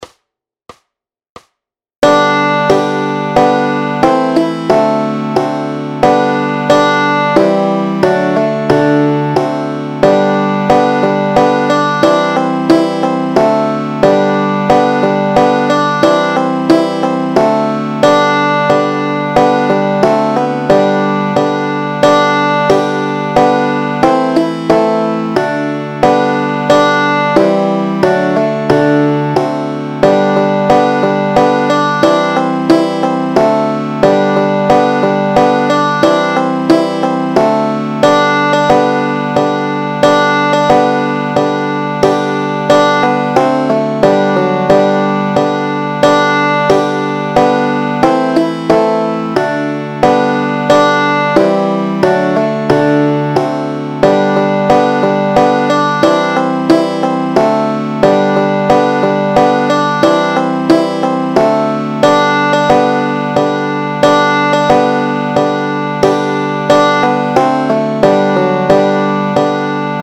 Noty, tabulatury, akordy na banjo.
Hudební žánr Vánoční písně, koledy